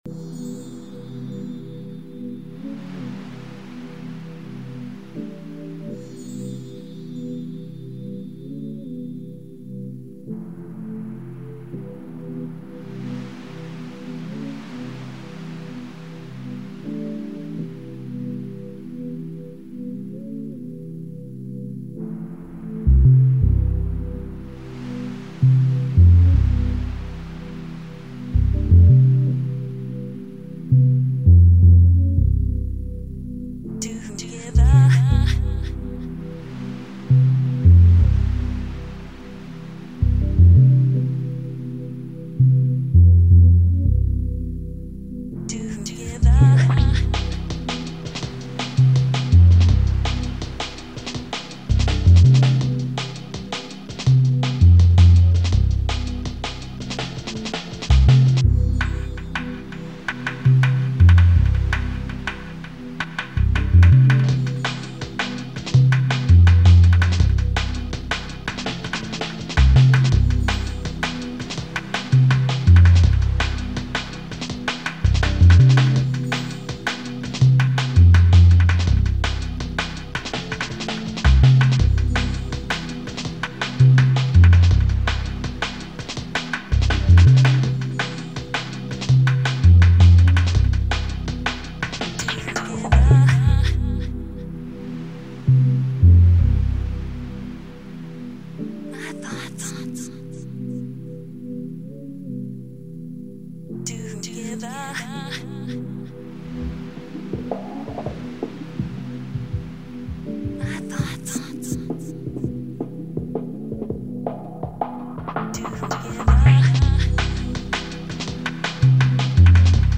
D+B idea
recorded live in the studio onto cassette